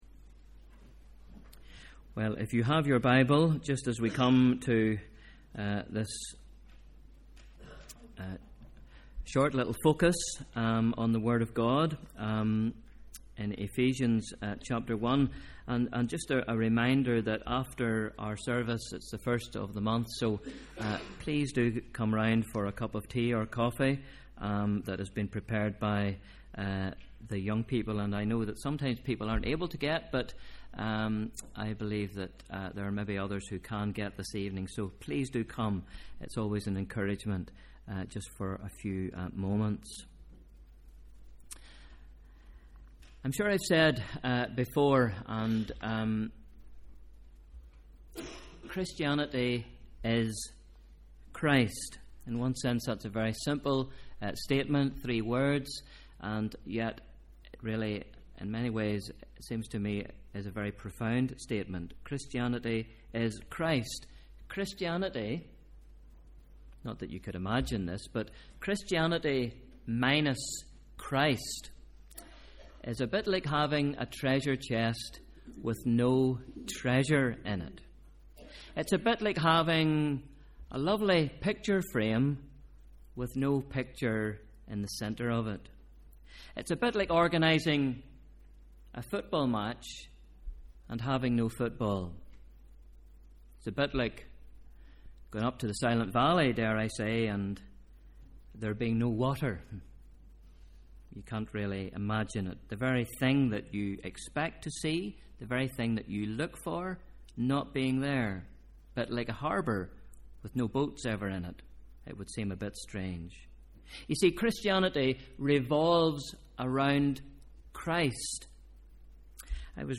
Sunday 3rd March 2013: Evening Service